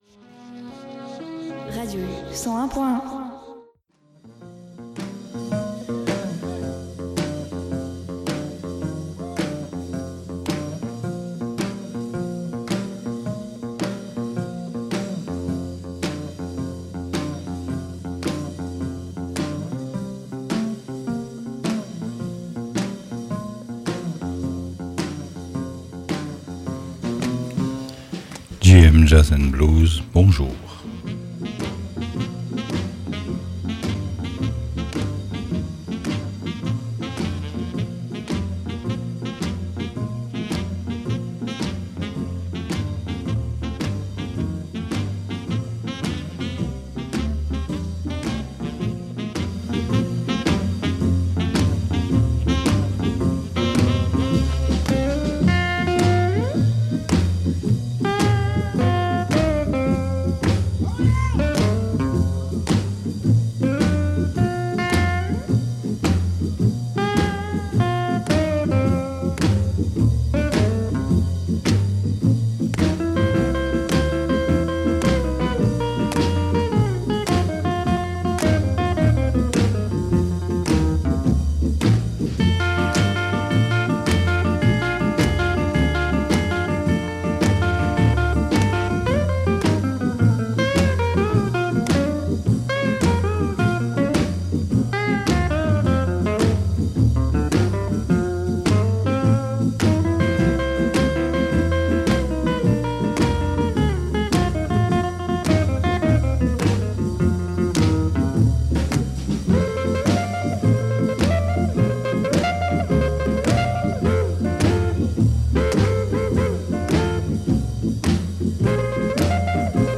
cornet, vocal
banjo
piano
clar, alto sax, ténor sax
sextet français